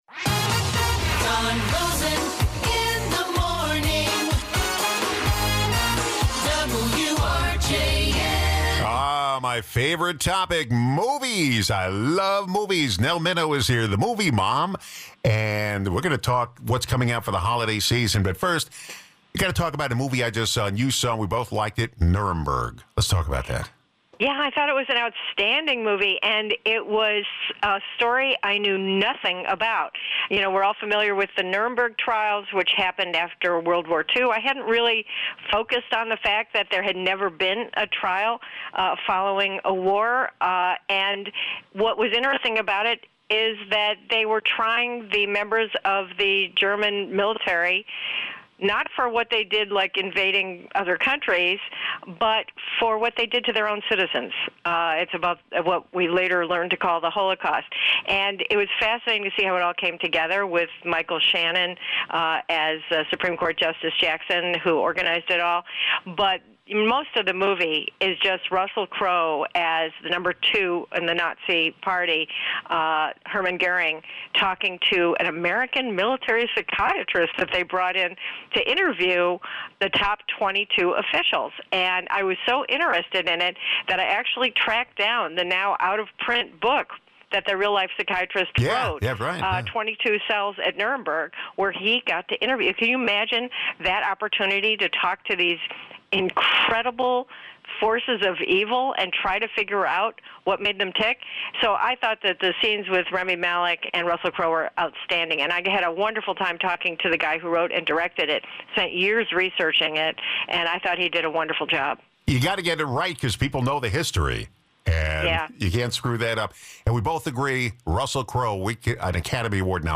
Holiday Movies with Movie Mom Guests: Nell Minow